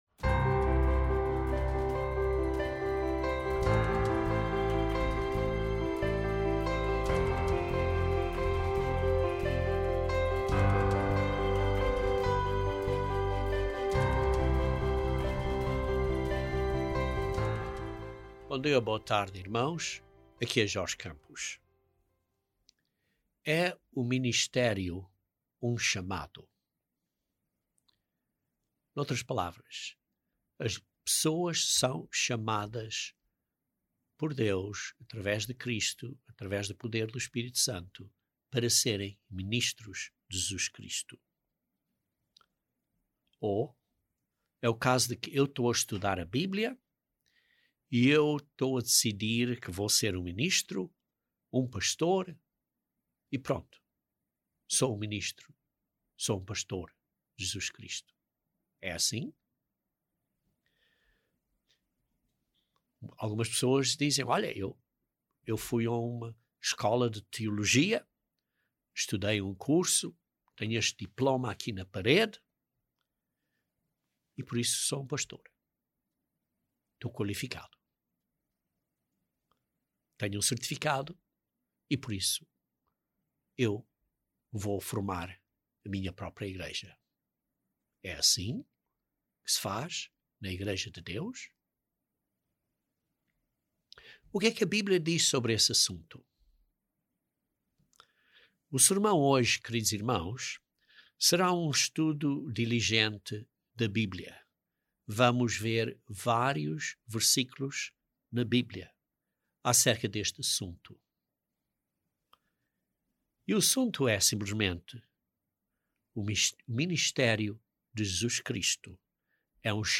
| A Igreja de Deus Unida